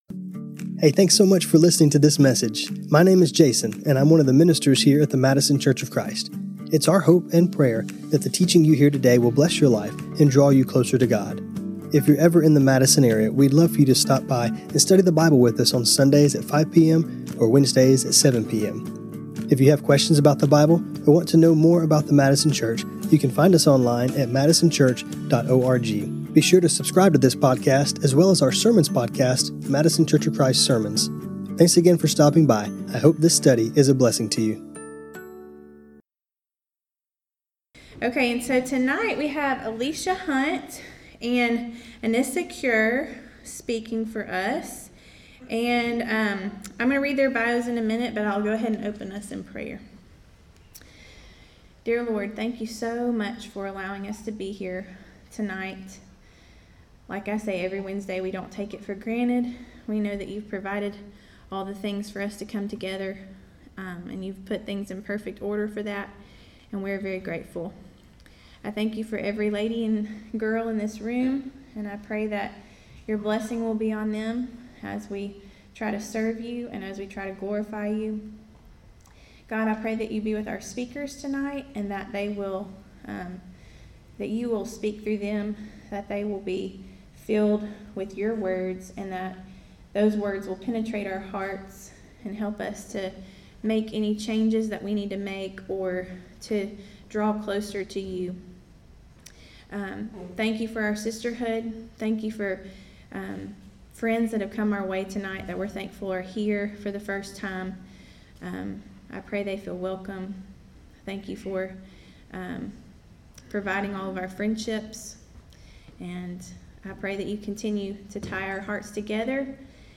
When we go through difficult times and even wonderful times, God has given us something that is constant, His word. In this class, we will hear from some of our own sisters as they share the scriptures that have carried them through different life circumstances both good and bad.